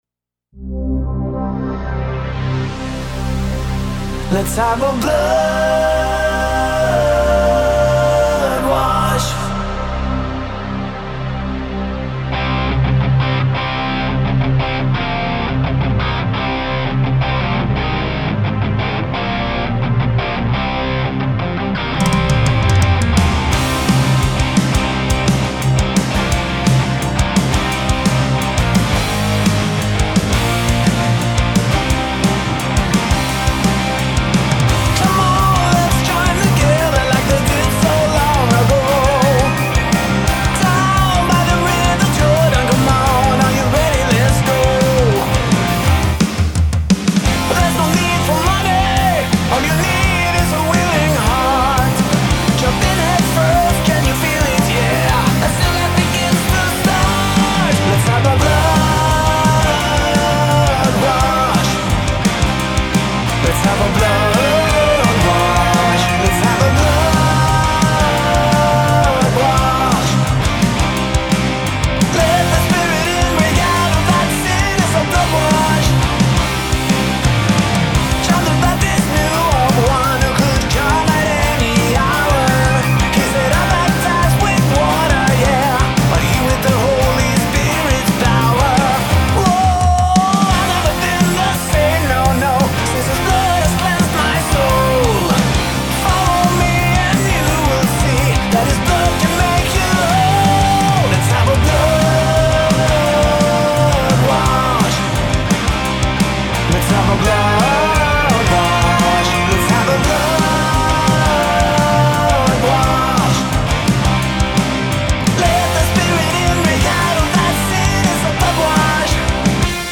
Christian rock